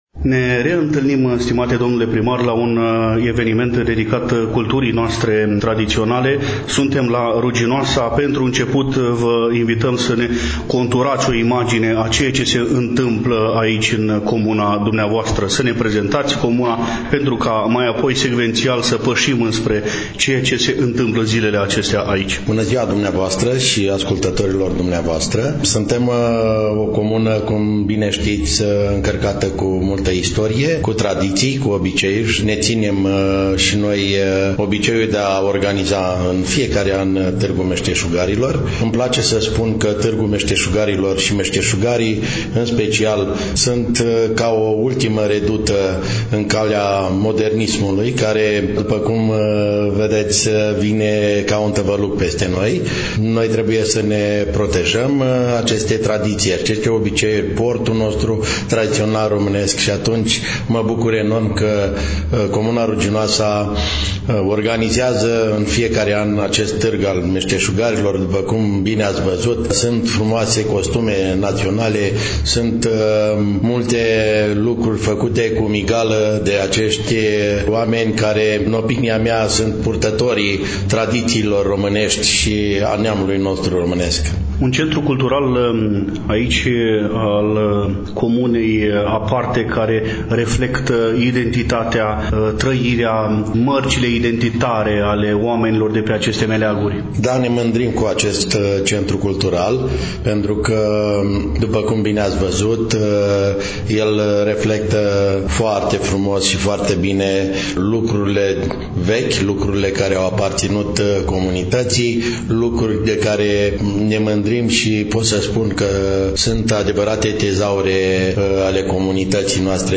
1_Danut-Nechifor-Primar-Ruginoasa-AMANUNTE-5-52.mp3